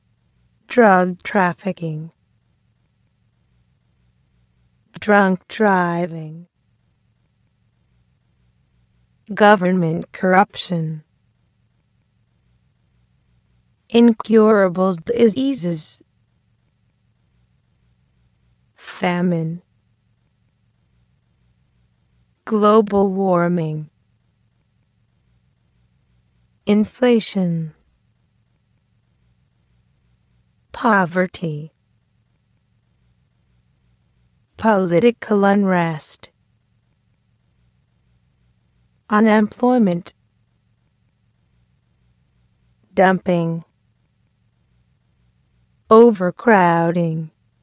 This glossary focuses on various nouns used to discuss world problems. Please listen and repeat twice.